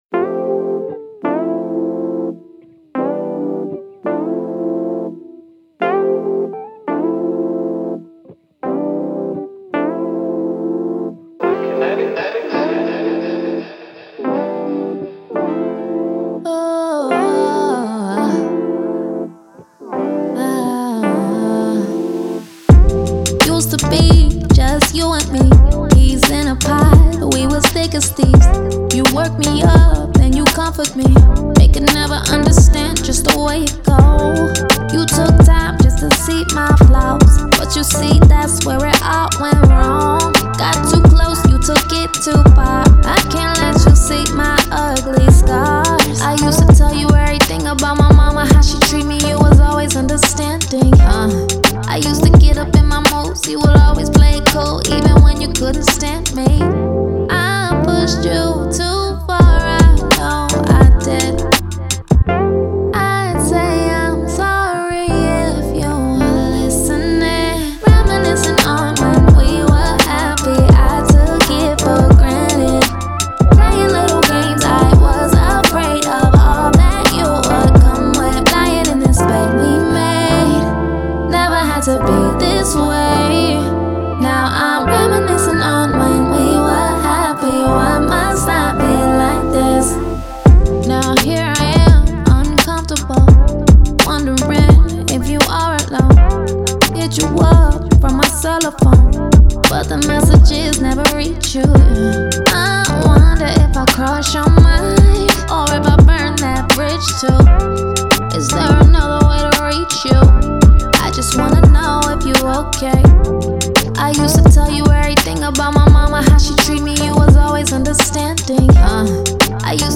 Afrobeat, R&B
D Minor